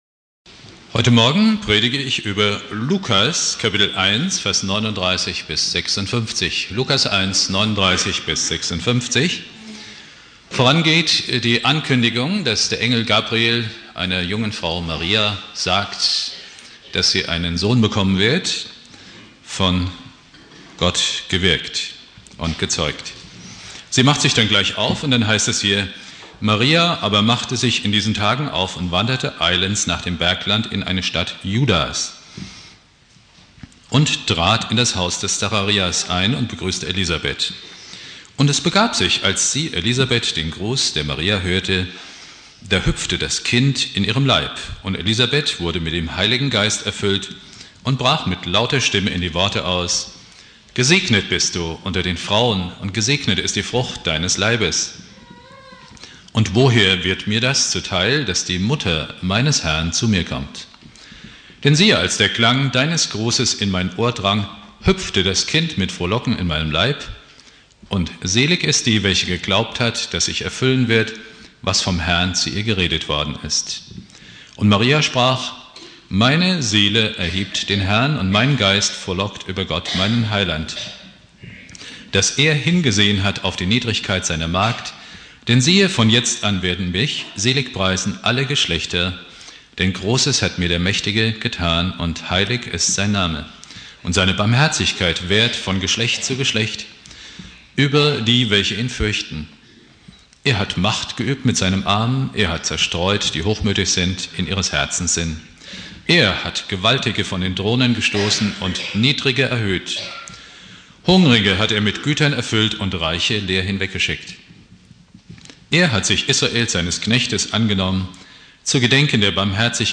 Predigt
4.Advent Prediger